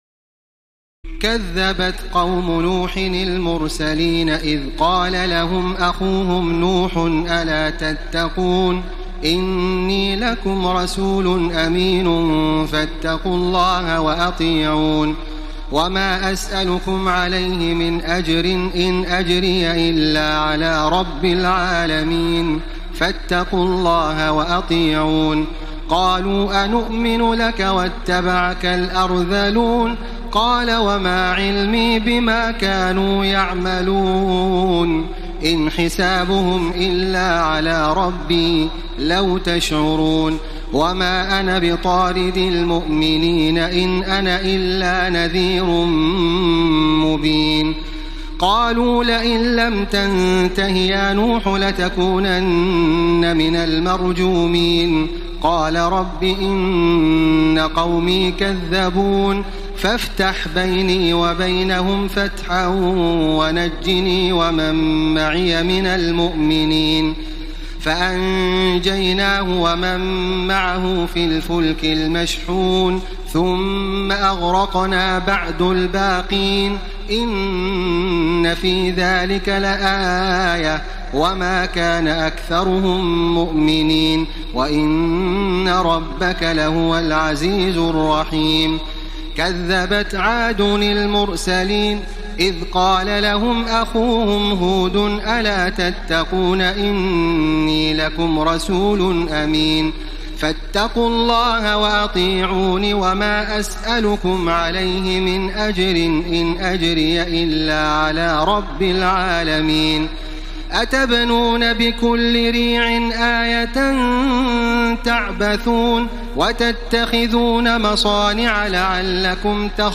تراويح الليلة الثامنة عشر رمضان 1434هـ من سورتي الشعراء (105-227) والنمل (1-59) Taraweeh 18 st night Ramadan 1434H from Surah Ash-Shu'araa and An-Naml > تراويح الحرم المكي عام 1434 🕋 > التراويح - تلاوات الحرمين